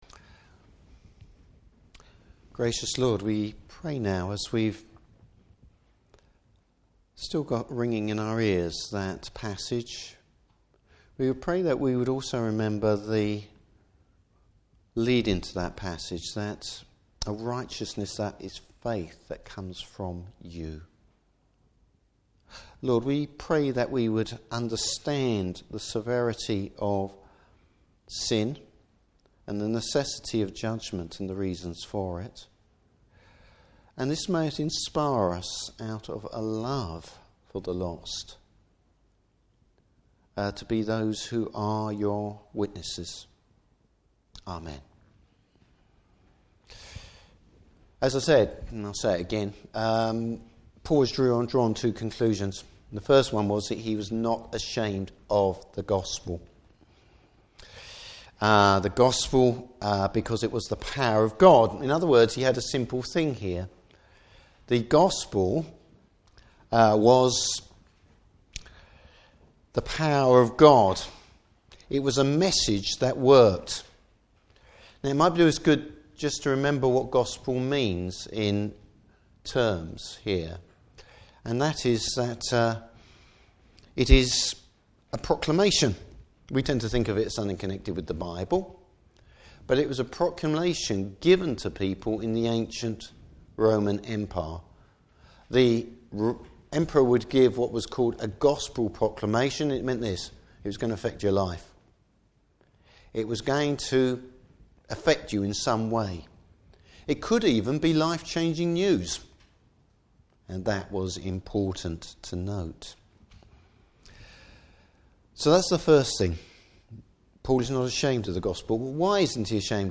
Service Type: Morning Service The consequences of God’s anger at sin.